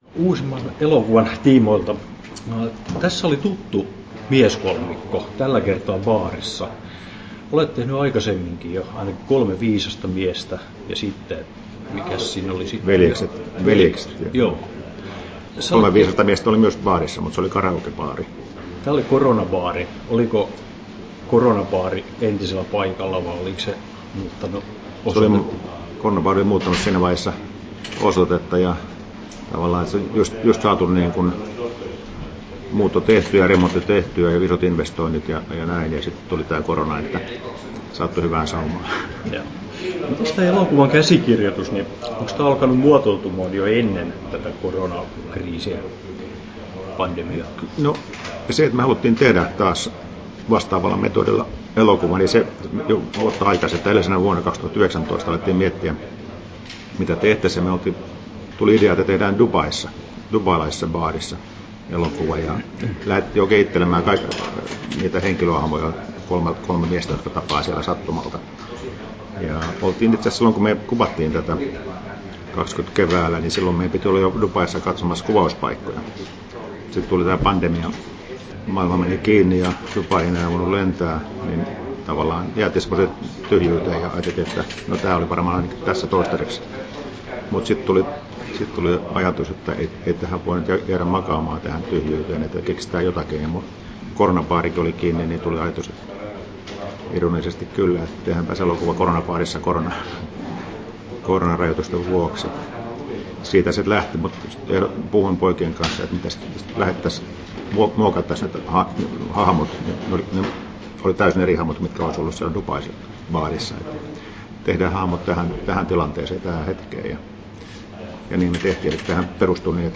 Haastattelussa Mika Kaurismäki Kesto: 11'37" Tallennettu: 05.10.2021, Turku Toimittaja